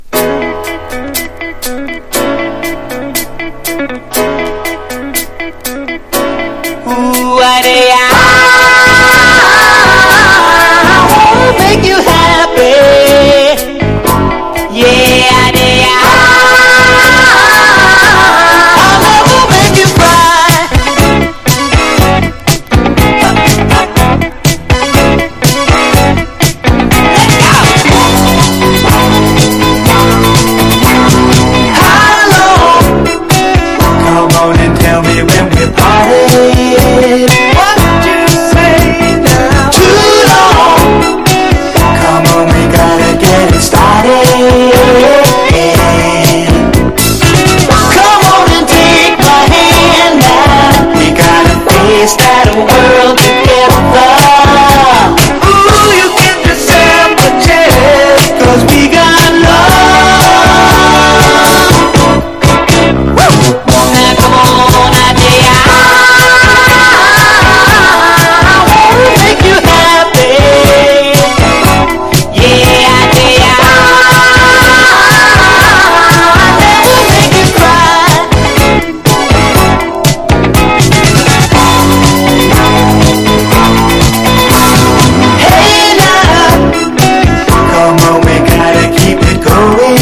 # SSW / FOLK# AOR# VOCAL & POPS# 80’s ROCK / POPS